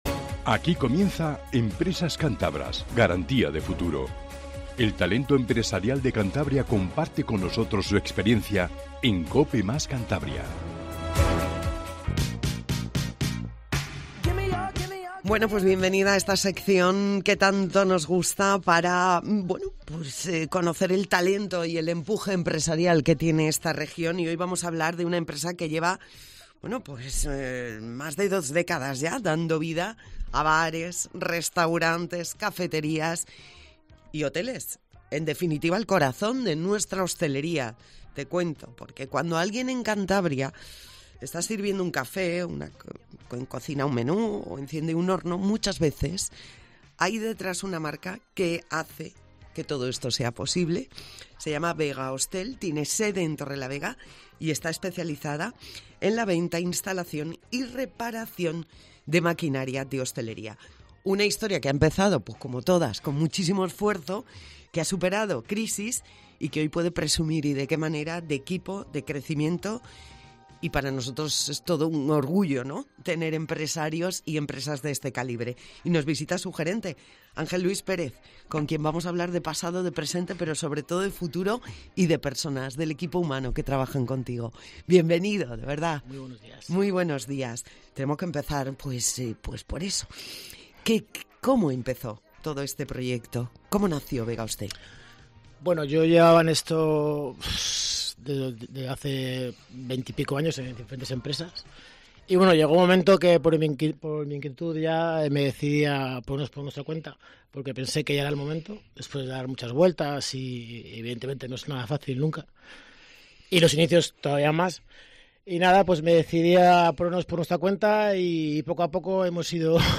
Entrevista de empresas cántabras